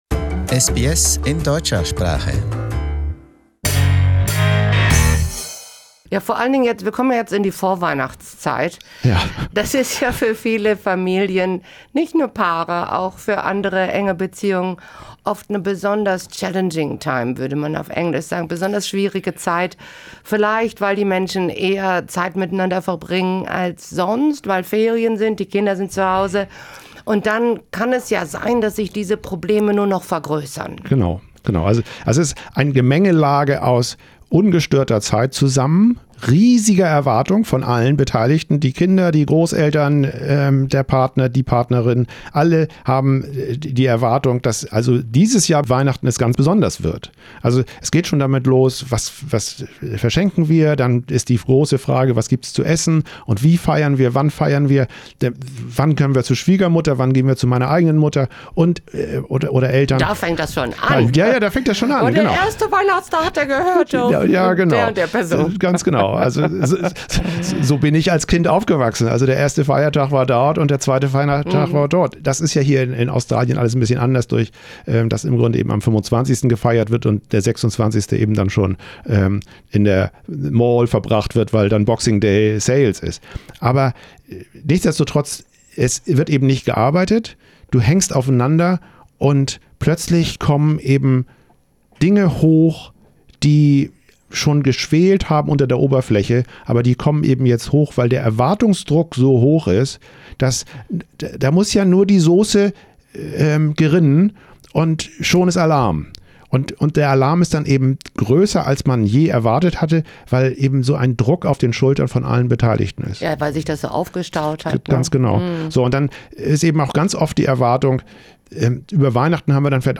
We talk about how to make family holidays so everyone can relax. The first part of the interview is about how to deal with relationship problems in general, in this second part we come to the Christmas part, in which we talk about how we can get through the holidays well.